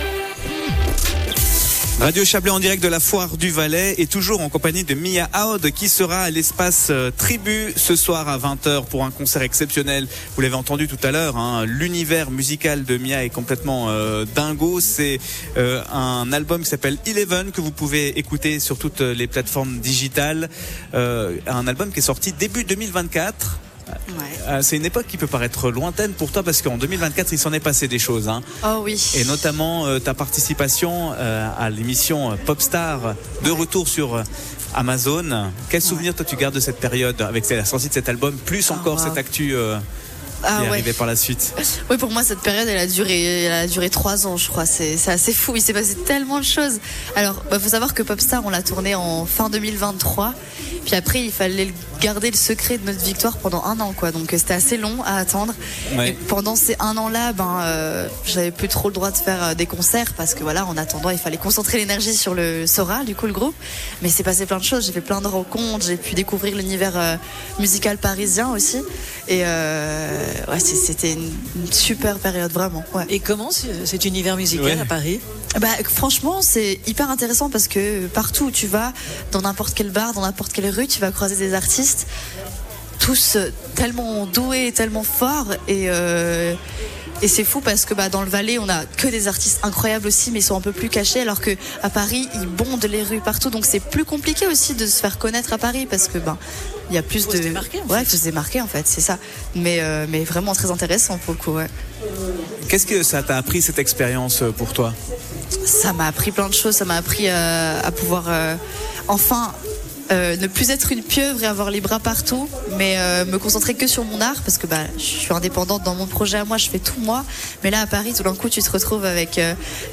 de passage sur le stand de la radio avant son concert